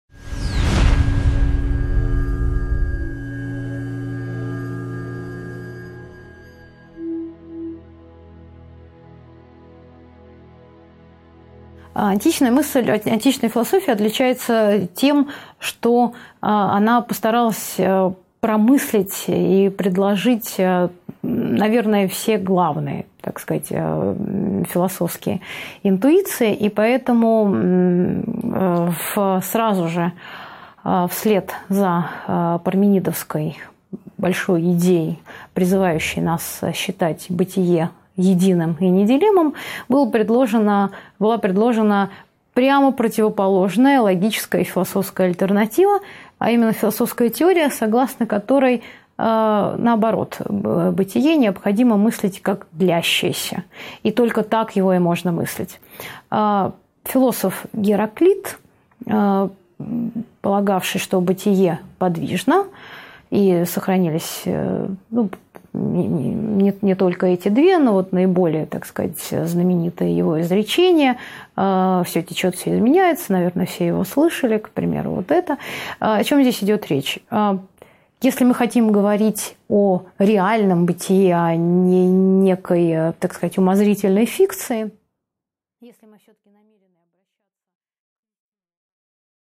Аудиокнига 2.3 Натурфилософия. Гераклит, Демокрит и парадкосы элеатов | Библиотека аудиокниг